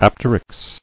[ap·ter·yx]